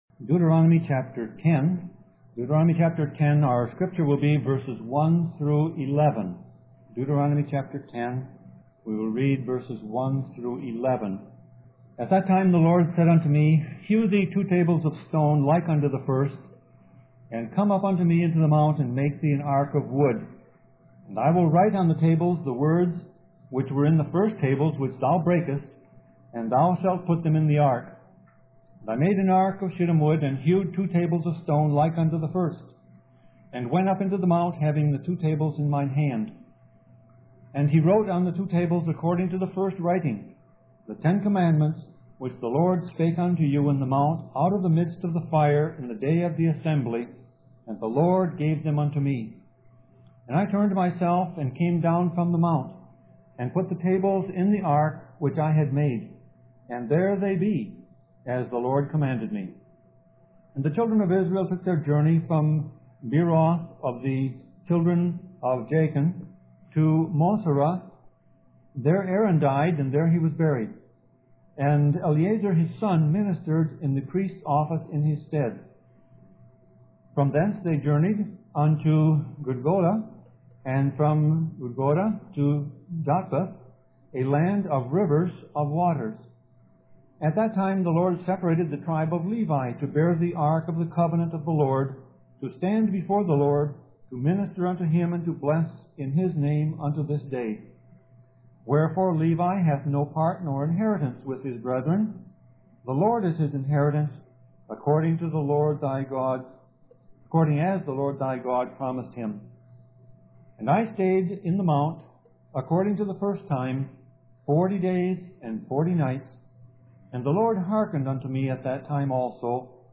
Sermon Audio Passage: Deuteronomy 10:1-11 Service Type